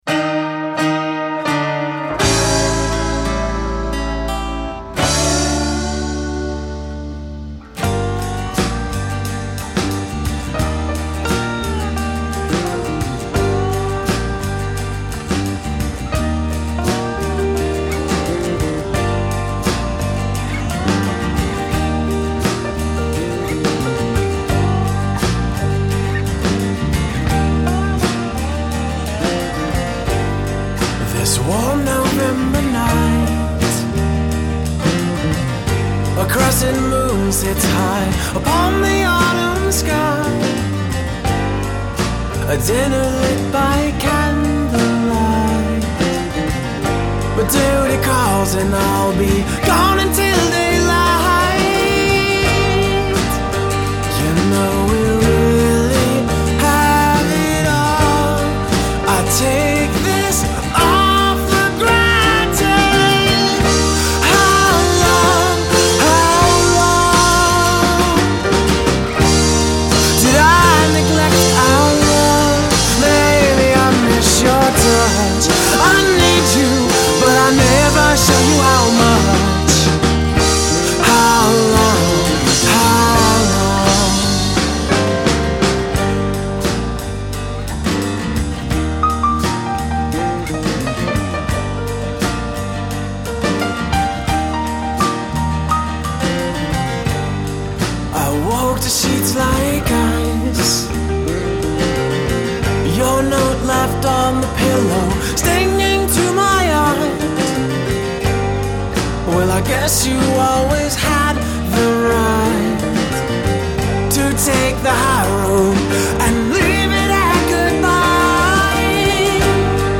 Guitar, Dobro, Vocals
Drums
Piano
Bass Guitar